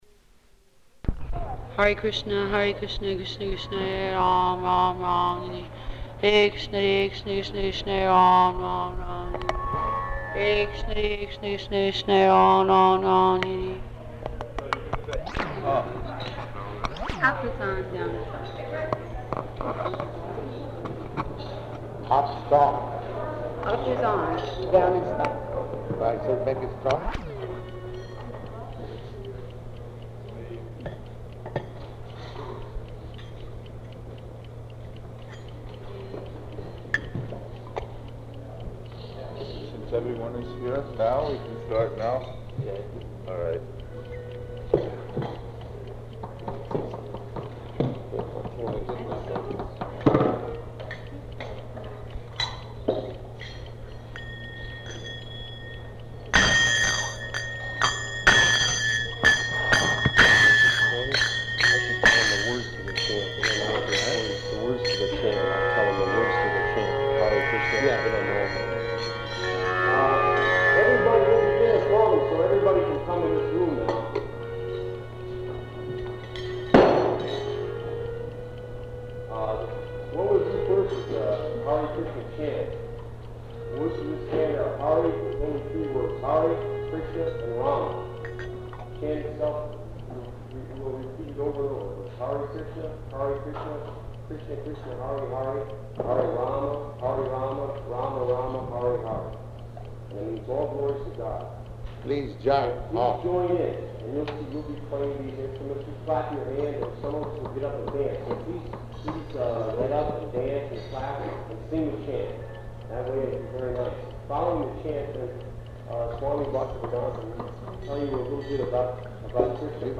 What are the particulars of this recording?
-- Type: Lectures and Addresses Dated: August 13th 1968 Location: Montreal Audio file